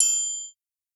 Southside Percussion (25).wav